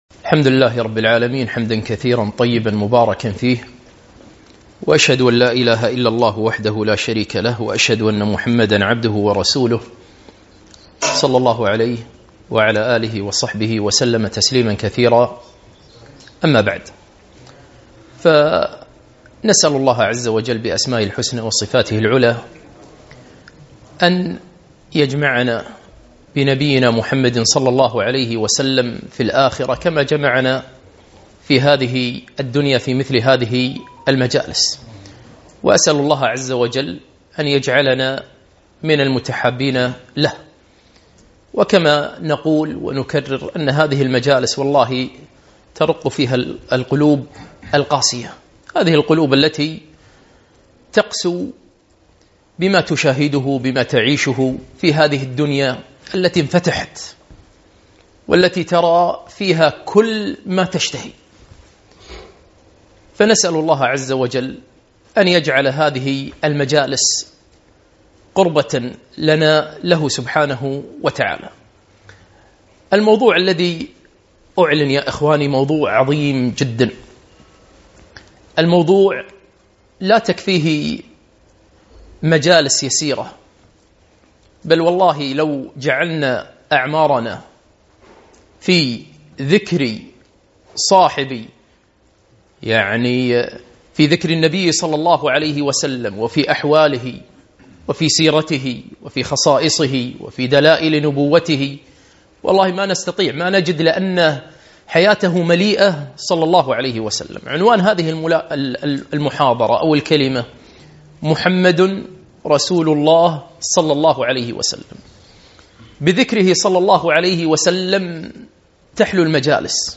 محاضرة - محمد رسول الله صلى الله عليه وسلم